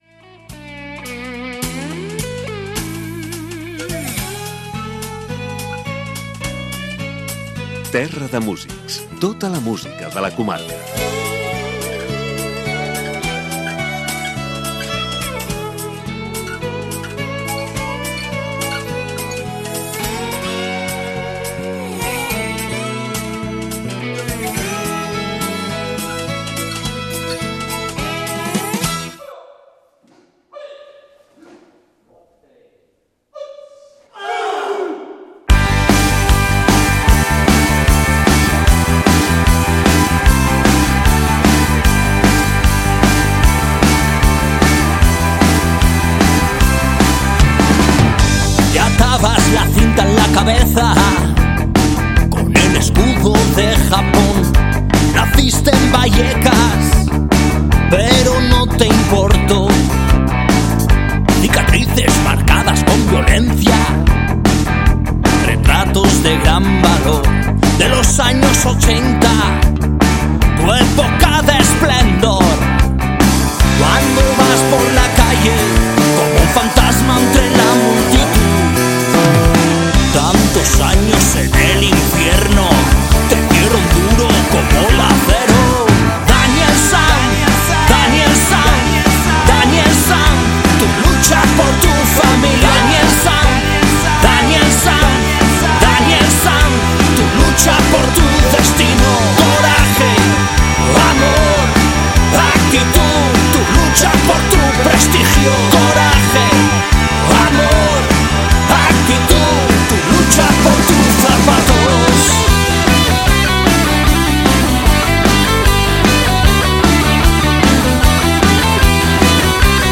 Amb algunes cançons en directe.